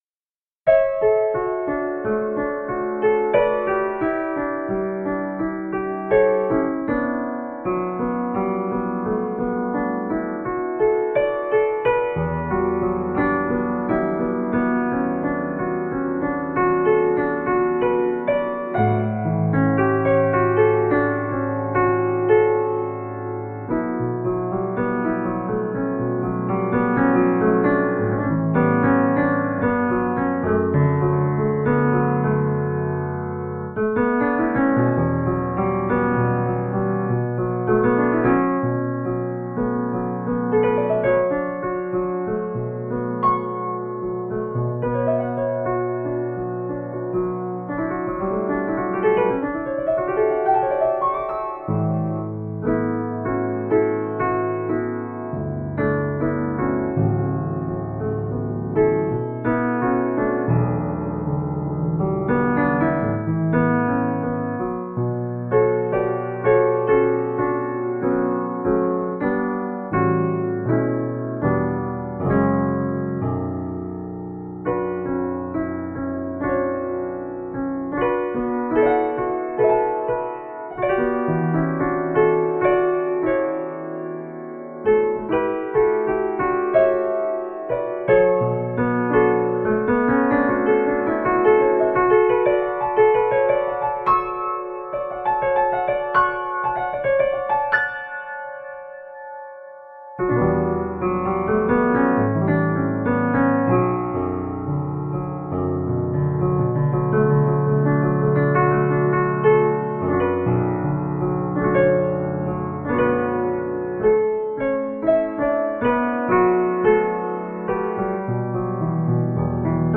Magical, heart inspiring music.